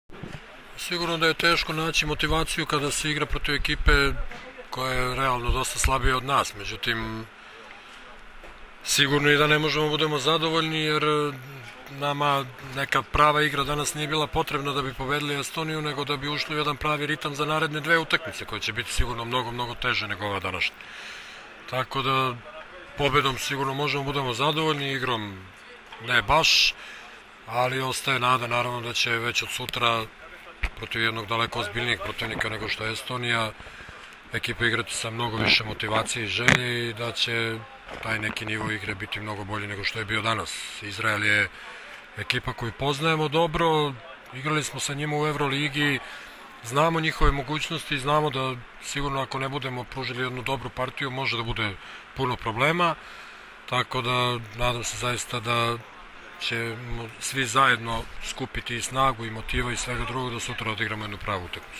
IZJAVA ZORANA TERZICA